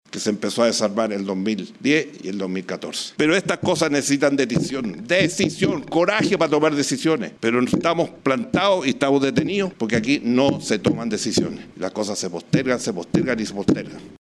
Golpeando el podio desde donde habló, Frei llamó a tomar decisiones con coraje.